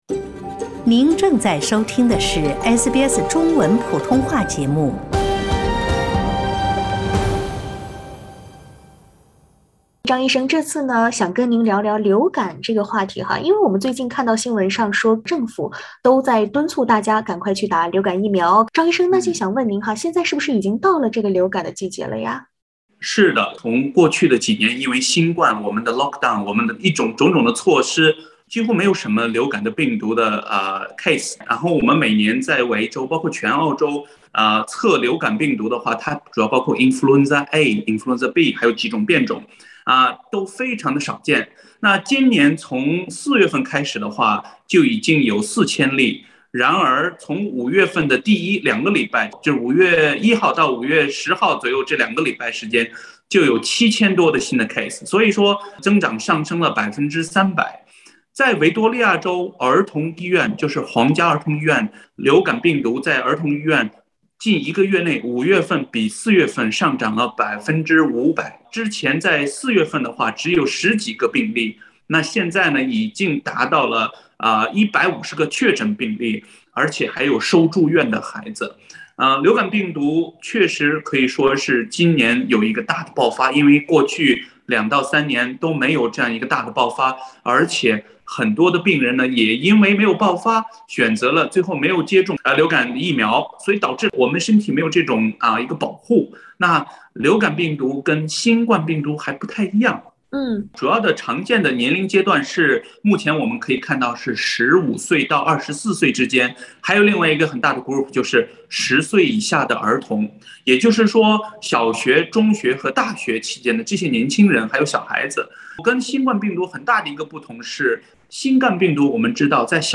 请听采访： LISTEN TO 华人医生解答：流感季来势汹汹，疫苗有哪几种？